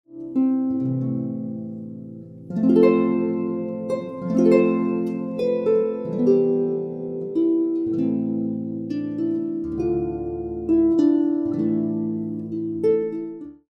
flute and harp